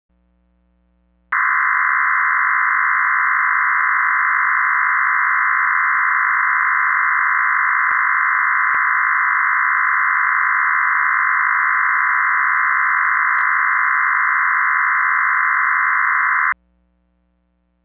Un-decoded Audio Sample - Which Can Be Fed Into Your Computers Microphone For De-coding.
FDMDV-undecoded.wav